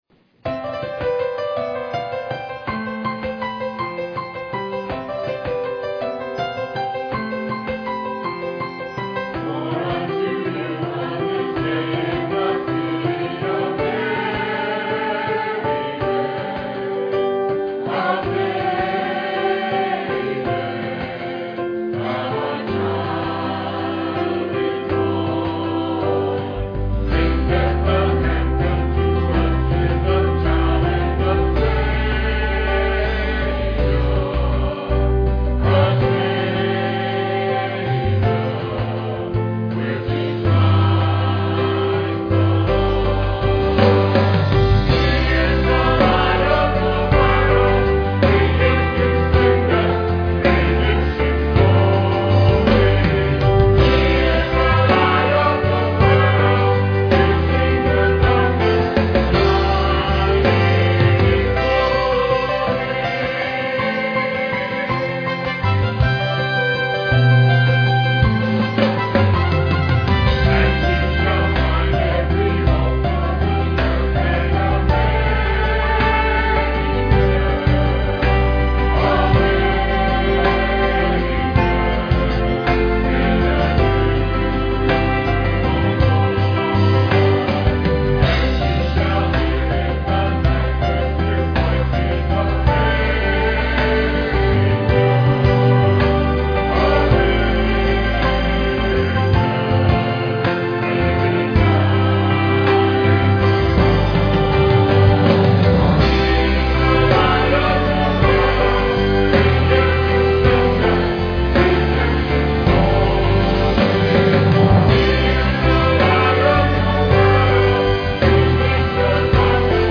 trio
piano.
Piano offertory